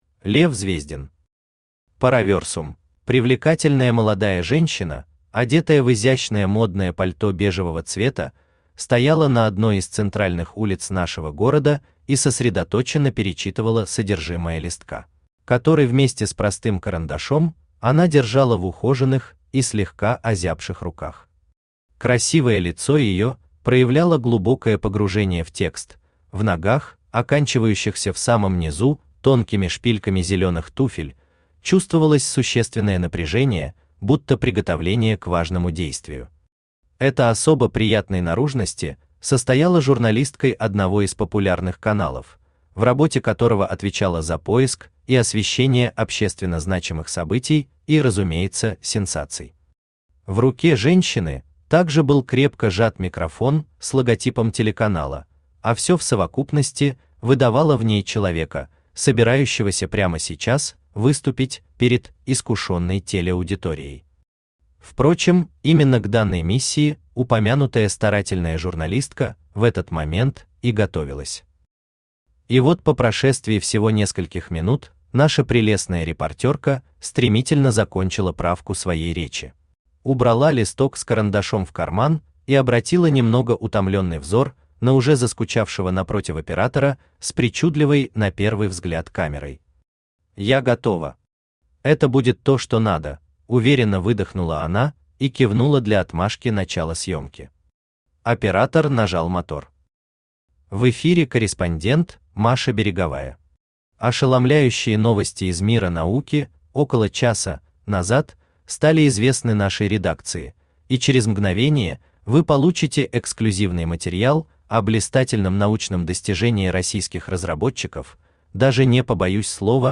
Аудиокнига Параверсум | Библиотека аудиокниг
Aудиокнига Параверсум Автор Лев Александрович Звездин Читает аудиокнигу Авточтец ЛитРес.